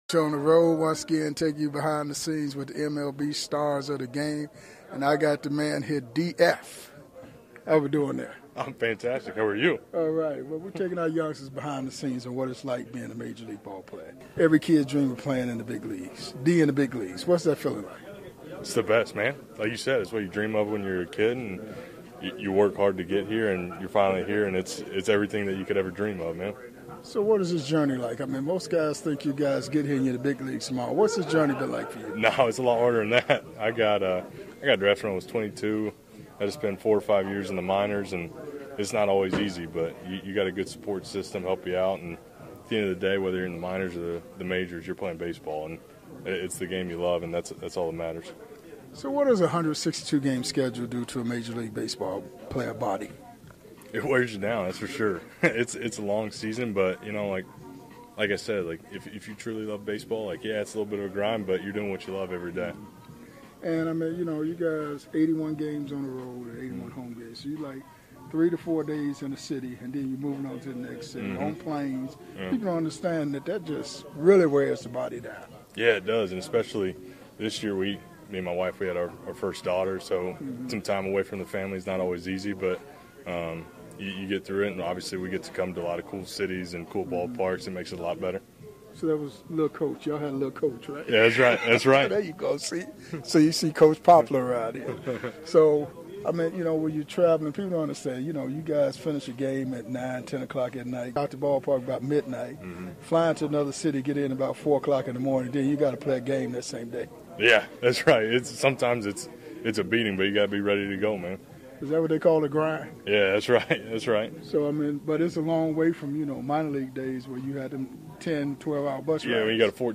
MLB Classic Interviews